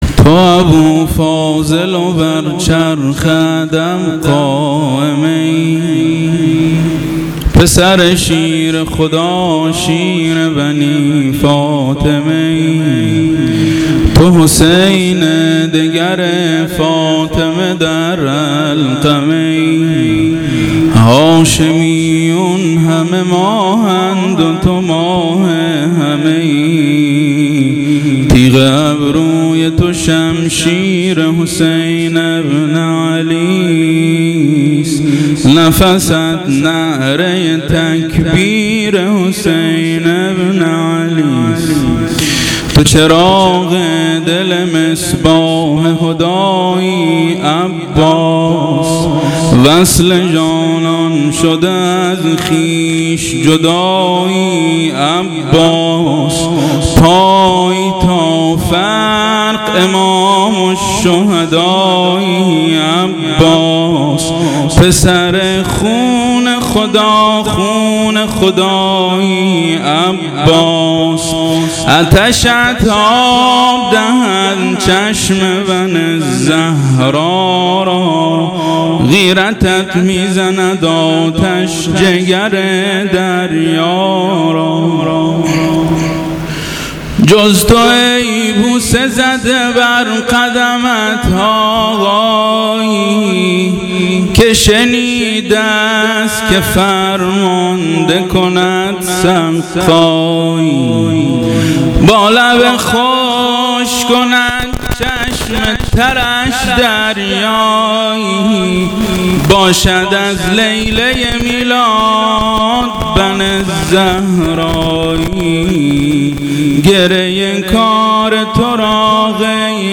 روضه-مدح شب تاسوعا محرم 1442 هیئت ناصرالحسین